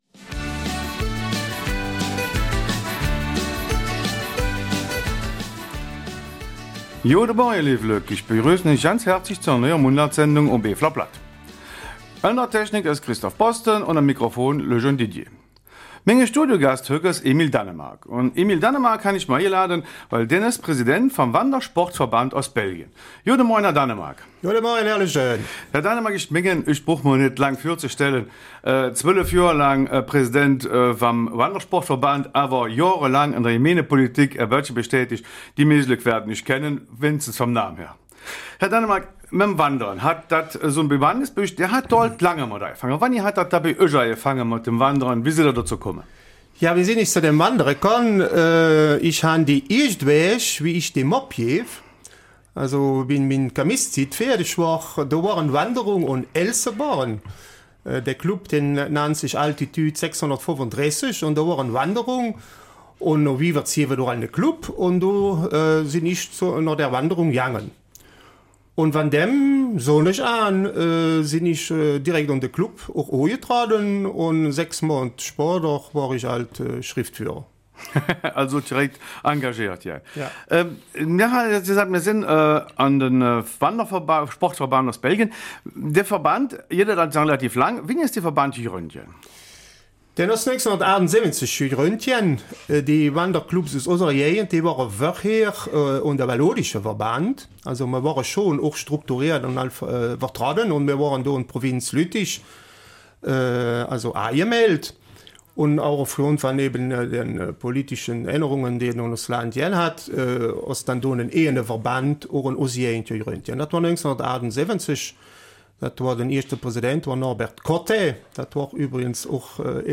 Eifeler Mundart: Wandersportverband Ostbelgien